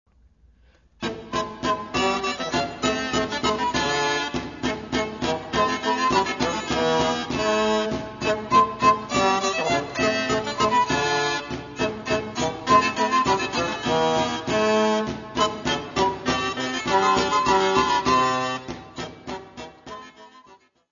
Mary's music : Songs and dances from the time of Mary Queen of Scots
Área:  Música Clássica